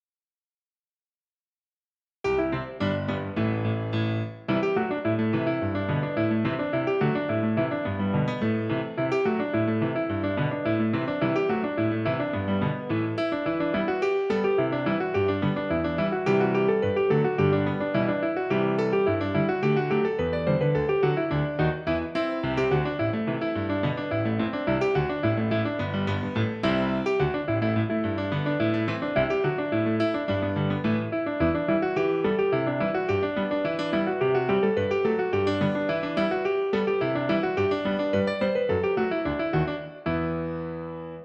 Piano accompaniment
Genre Celtic and Gaelic
Tempo 107
Rhythm Reel
Meter 4/4